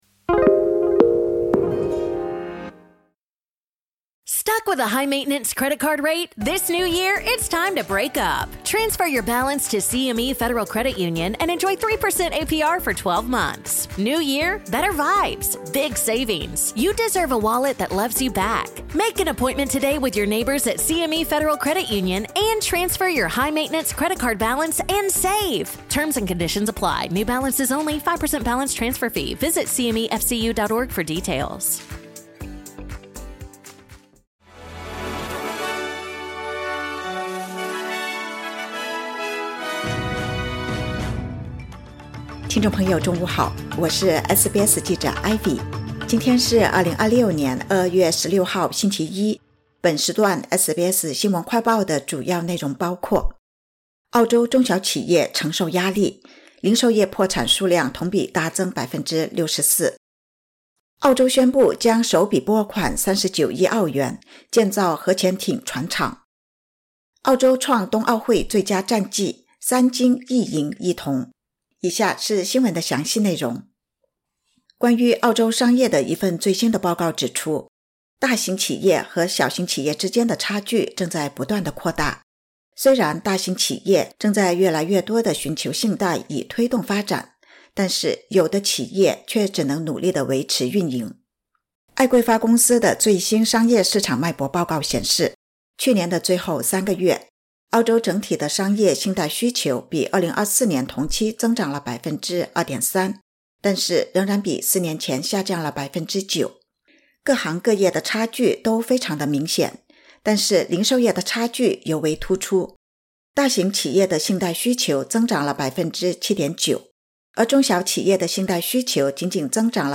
【SBS新闻快报】澳洲小型企业承压 零售业破产数量同比大增64%